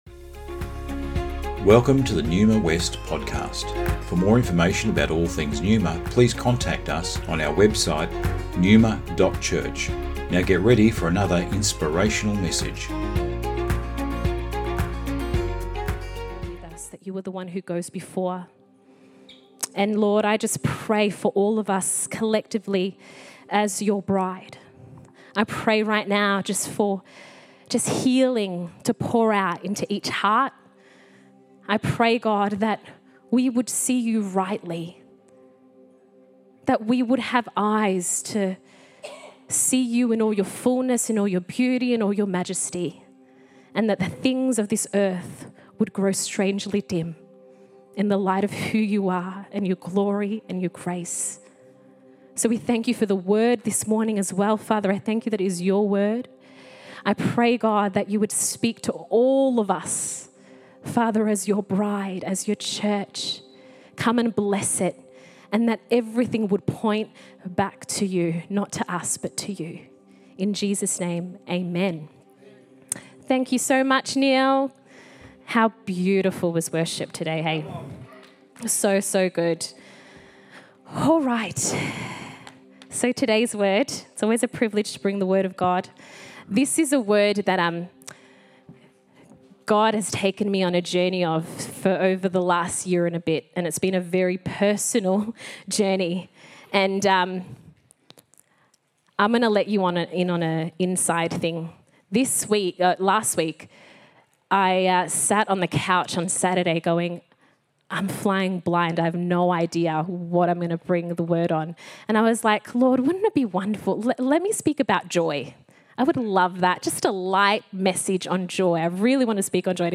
Originally recorded at Neuma Melbourne West Feb 25th 2024